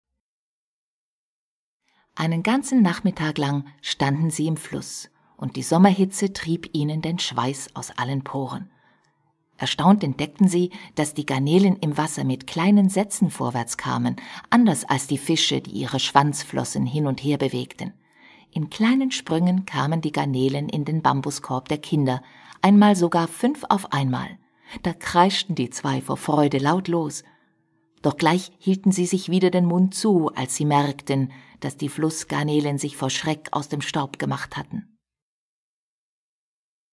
deutschsprachige Sprecherin (Schweiz) Verzauberung: großer Stimmumfang, schöne Modulation!
Sprechprobe: Industrie (Muttersprache):
german female voice over artist (switzerland)